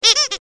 clock04.ogg